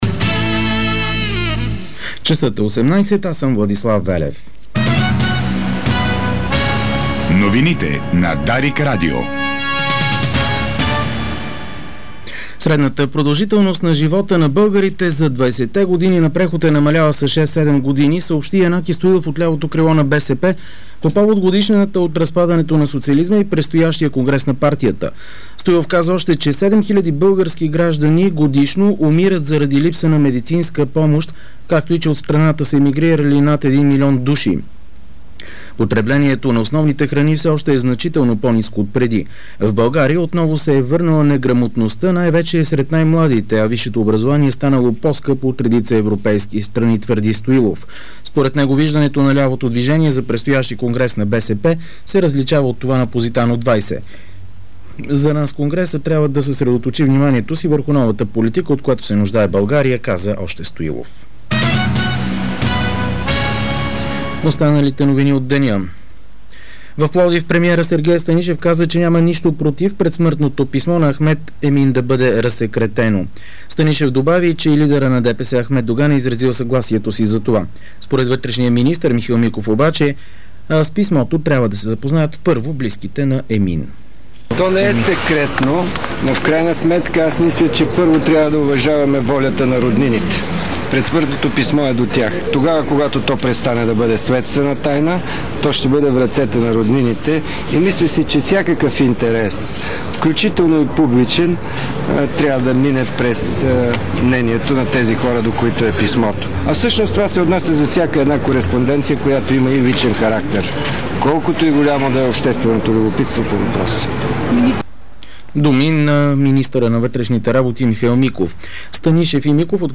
Обзорна информационна емисия - 09.11.2008